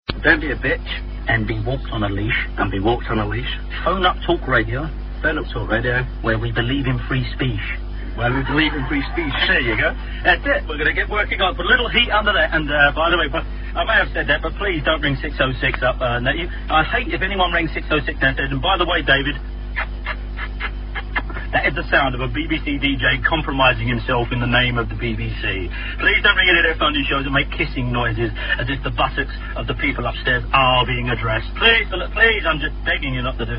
A speech by the Victor